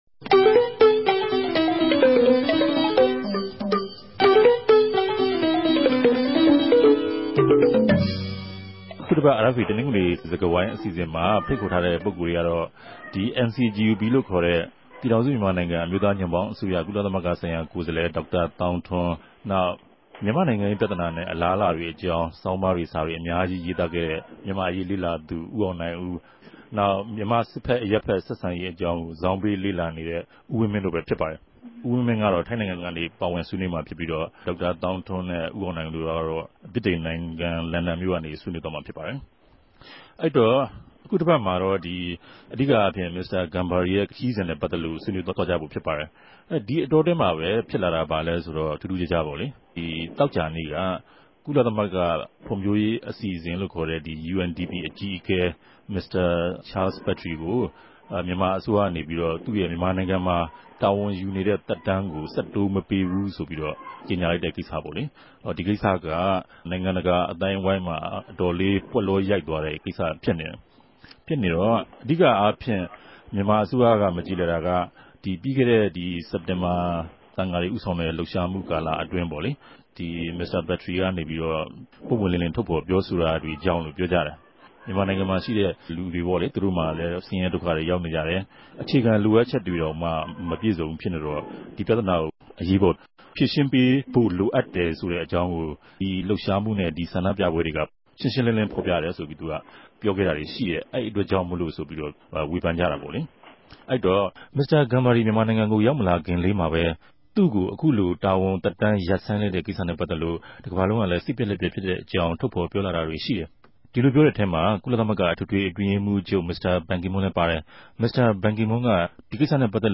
တပတ်အတြင်းသတင်းသုံးသပ်ခဵက် စကားဝိုင်း